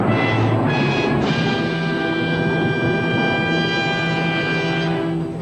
Play, download and share CYPH-Dramatisch original sound button!!!!
dramatic_JWbCSO1.mp3